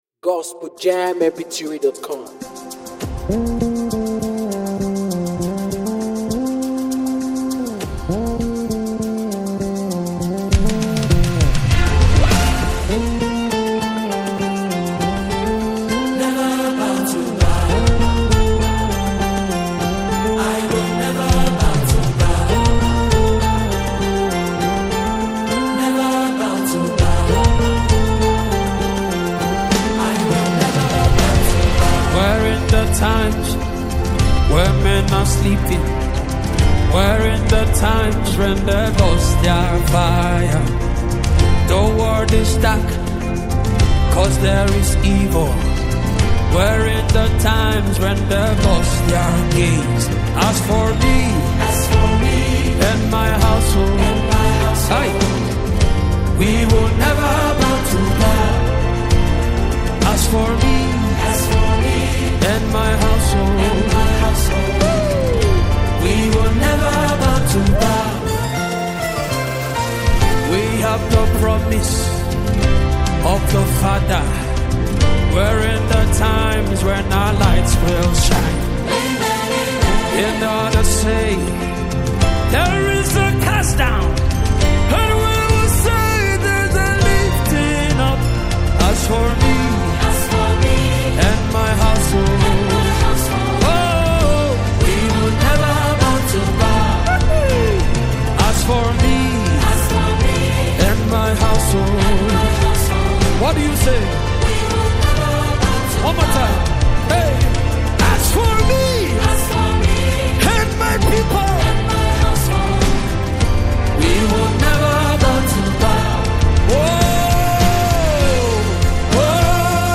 gospel
With passionate vocals and intense worship atmosphere